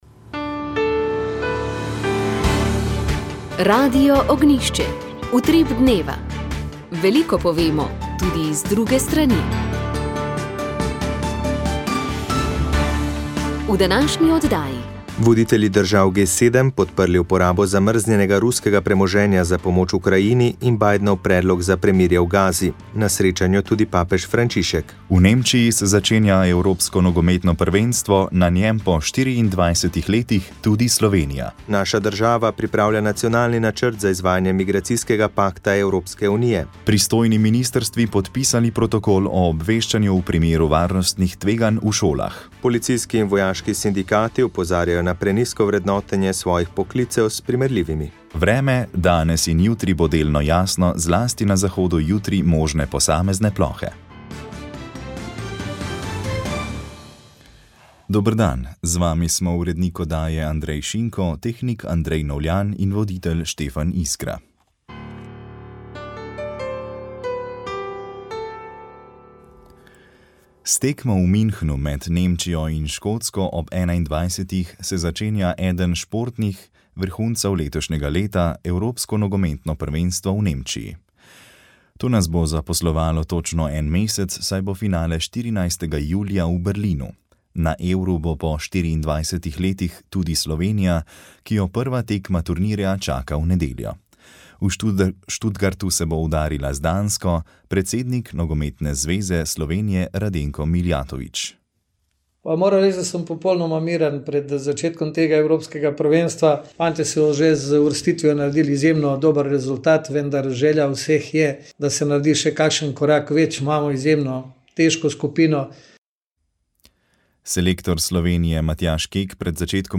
Minulo nedeljo je Geodetska uprava objavila nove posplošene vrednosti nepremičnin, ki odražajo stanje trga na 1. januar 2025. Na podlagi katerih lastnosti nepremičnin je bila določena njihova vrednost, kdaj bodo lastniki dobili potrdila in kaj lahko storijo, če se s posplošeno vrednostjo ne strinjajo? Naš gost je bil direktor Urada za množično vrednotenje mag. Dušan Mitrović.